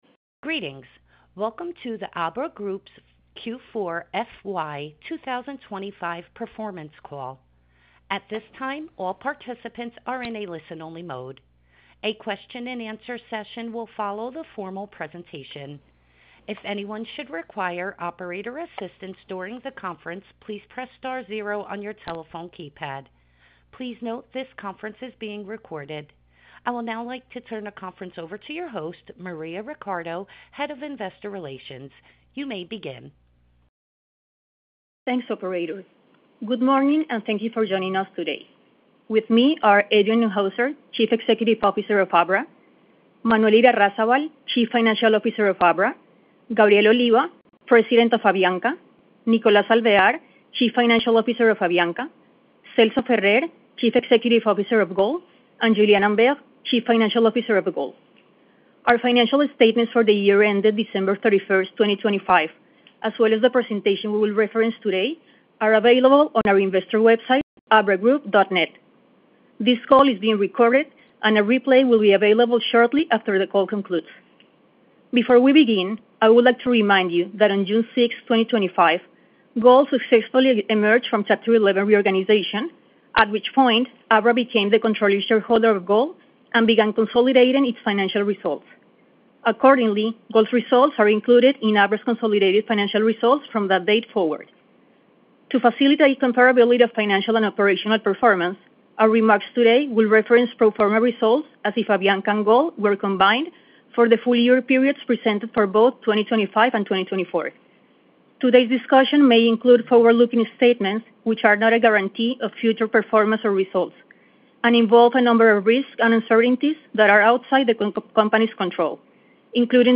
Meeting-Abra-Fourth-Quarter-and-Full-Year-2025-Earnings-Call.mp3